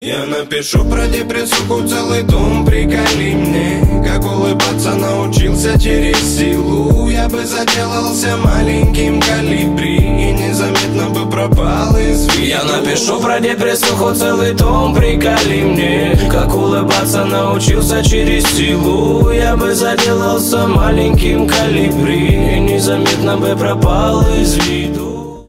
рэп , хип-хоп